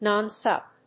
音節non・such 発音記号・読み方
/nˈʌns`ʌtʃ(米国英語)/